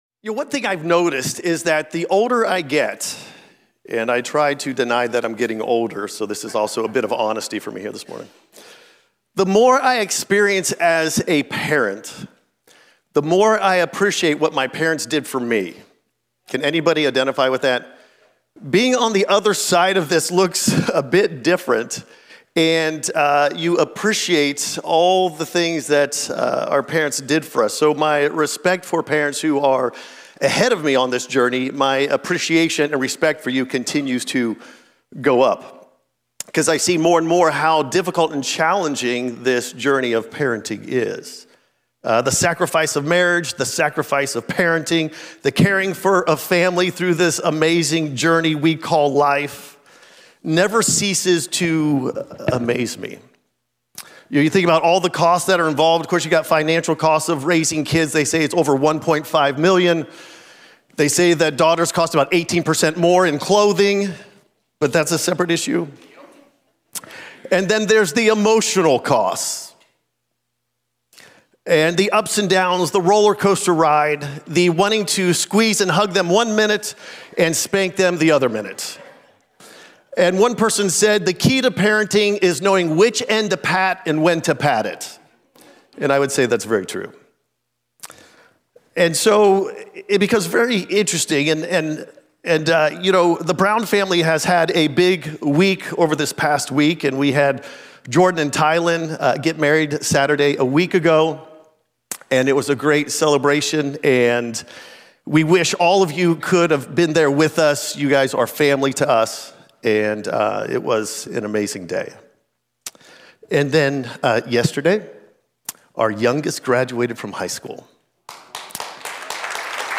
This podcast is dedicated to providing audio for Cornerstone messages and sermon series at the Americus Campus.